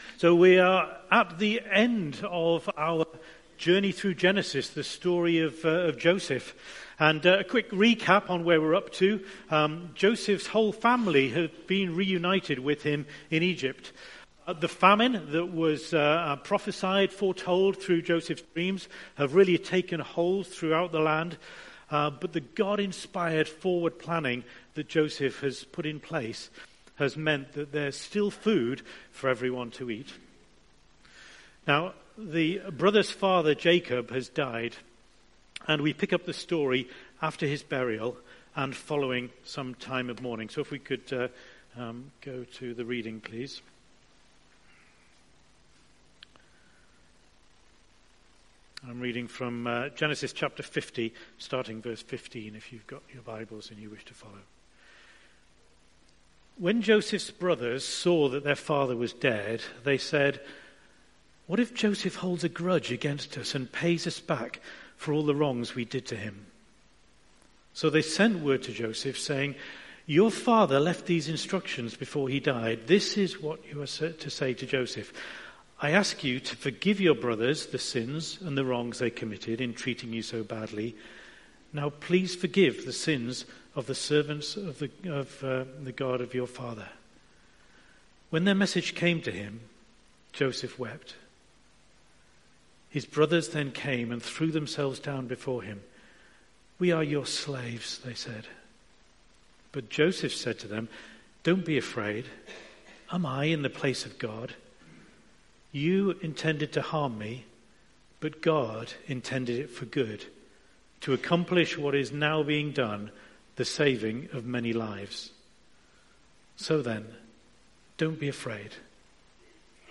Preacher
Passage: Genesis 50:15-21 Service Type: Sunday Morning